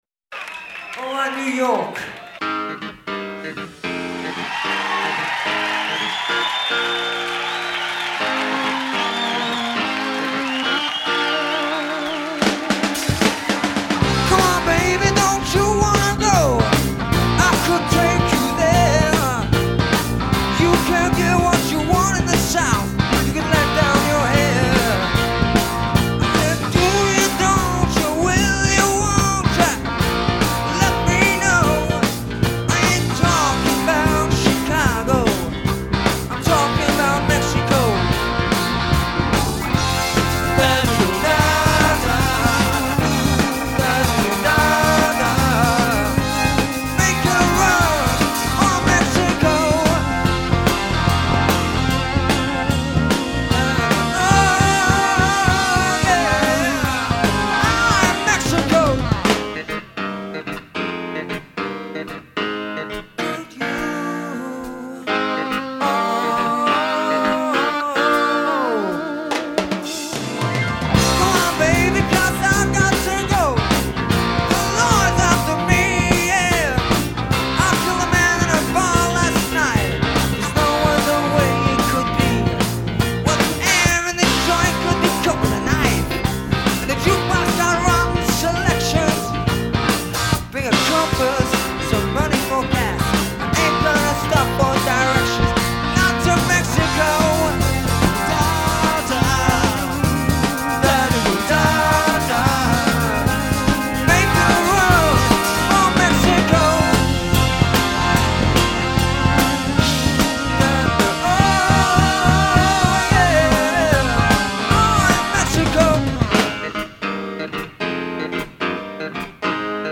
English band
American keyboard player